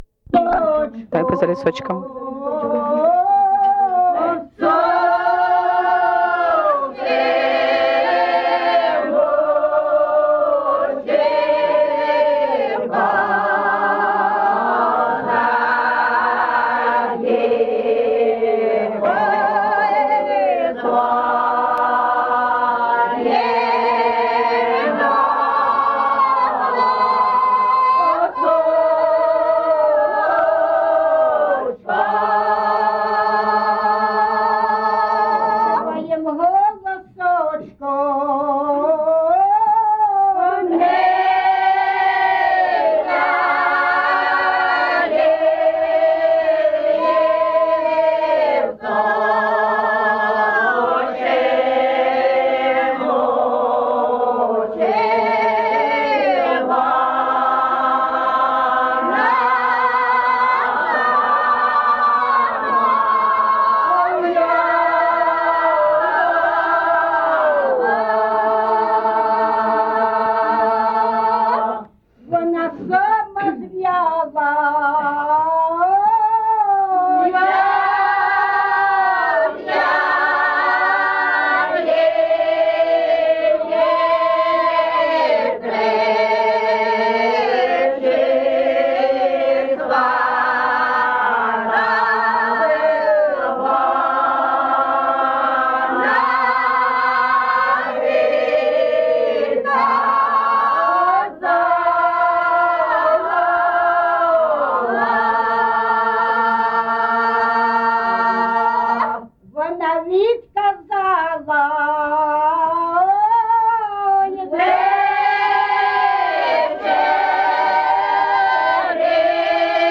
ЖанрПісні з особистого та родинного життя
Місце записус. Шарівка, Валківський район, Харківська обл., Україна, Слобожанщина